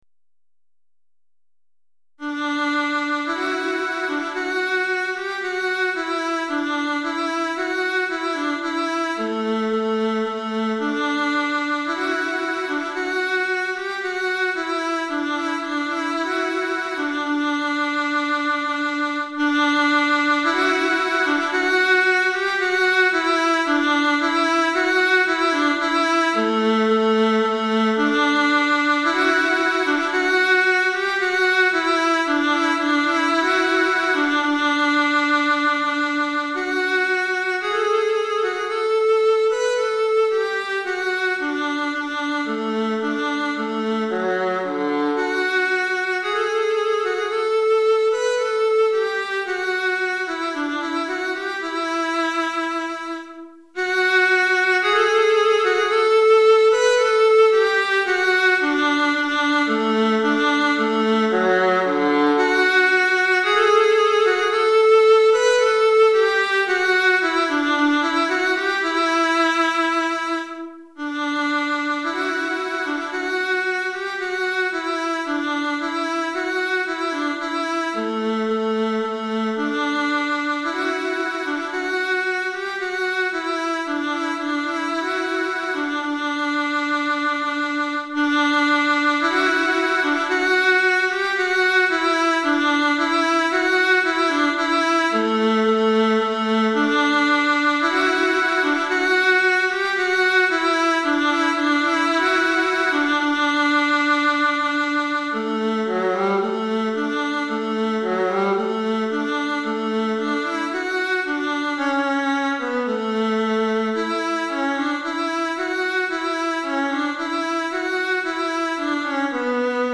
Alto Solo